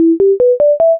extra_life.ogg